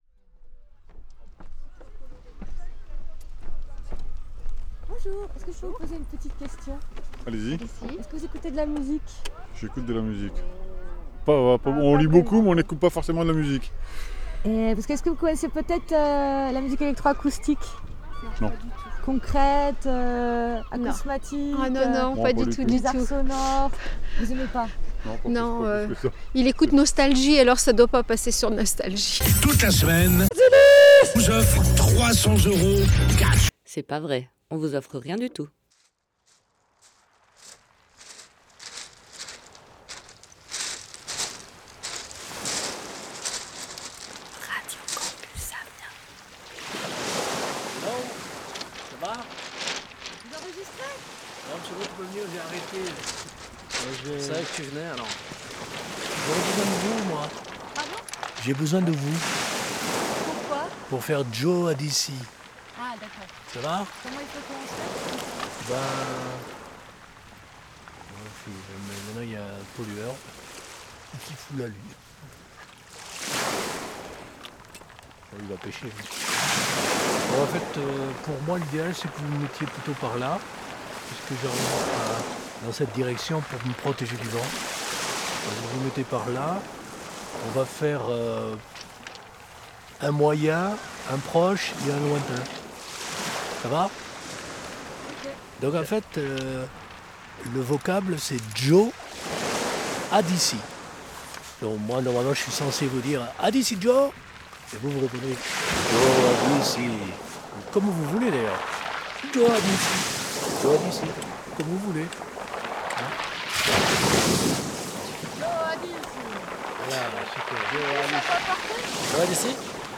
Une courbe concrète, bruitiste et éléctronique. On y écoute des espèces et des espaces de son, des extraits d´oeuvres et des petites formes avec leurs auteur(e)s. C´est encore une manière de mener une recherche intime sur le sonore, et ses possibles. Entrez dans un cinéma pour l´oreille.